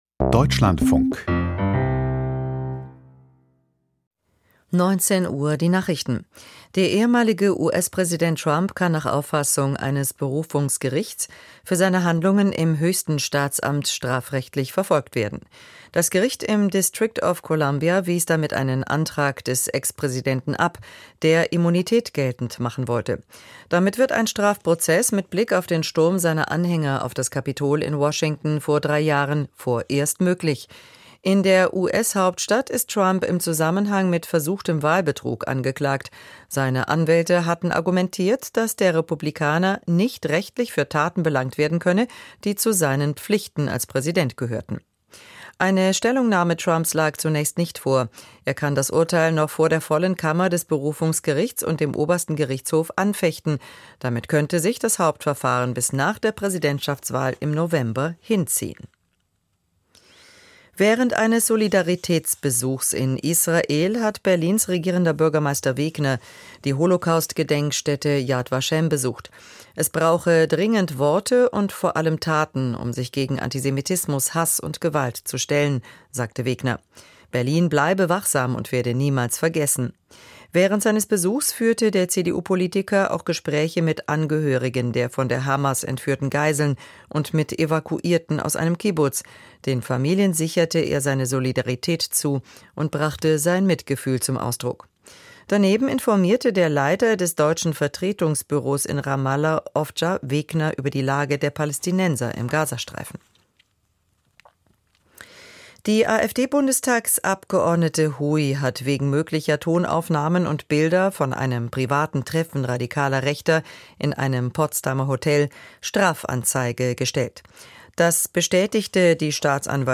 Russische Cyberangriffe - Interview mit Konstantin von Notz, Grüne